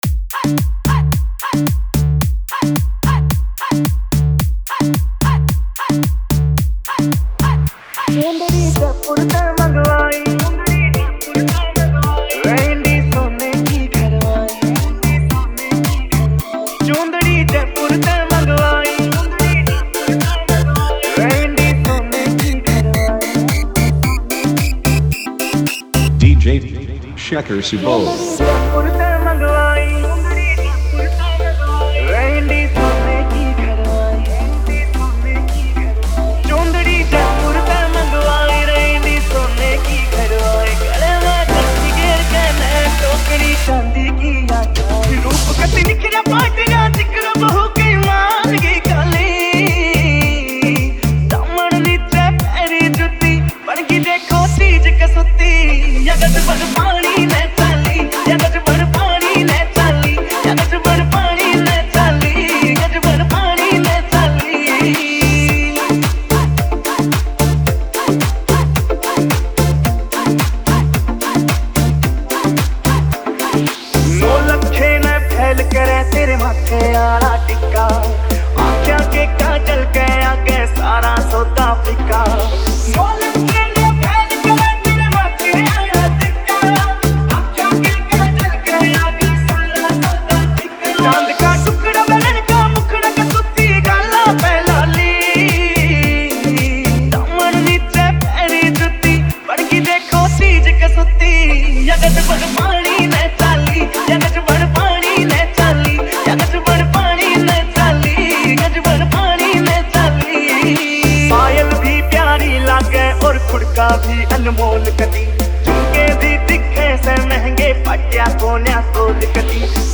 Haryanvi DJ Remix Songs